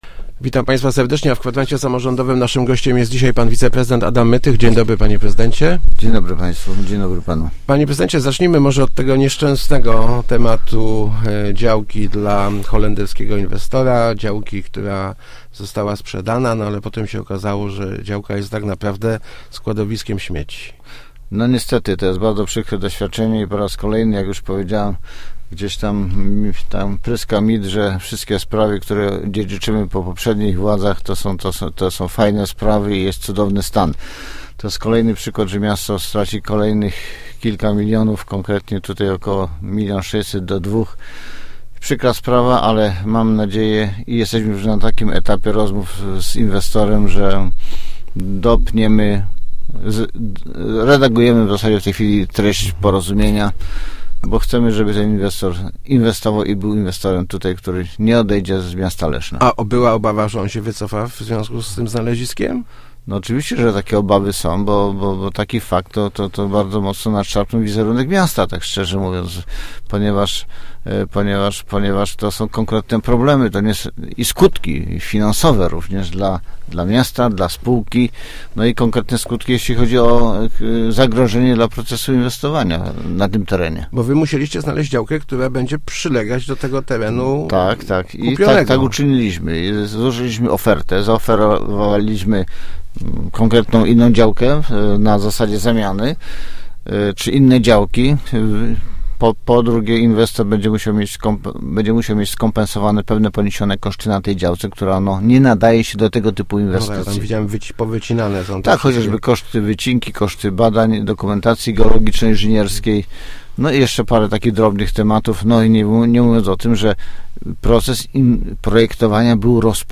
– Inwestycja mog�aby ruszy� latem przysz�ego roku – zapowiedzia� w Kwadransie Samorz�dowym wiceprezydent Adam Mytych.